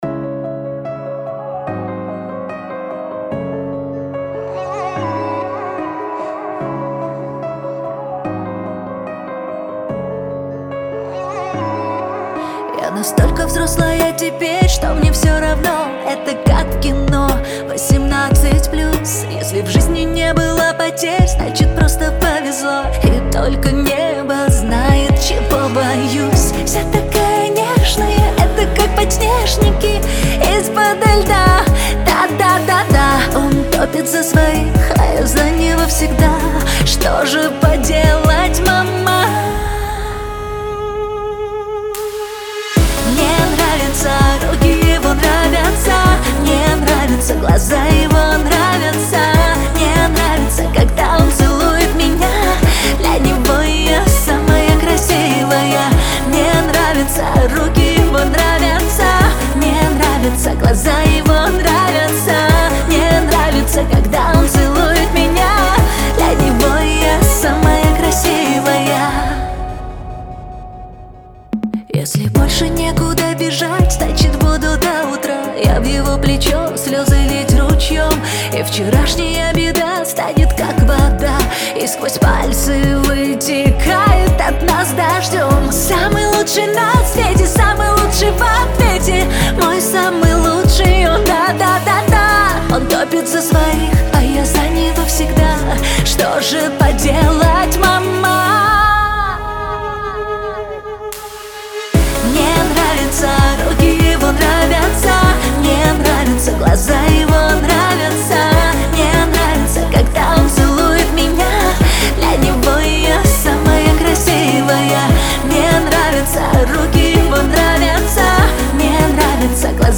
Категории: Русские песни, Эстрада, Поп.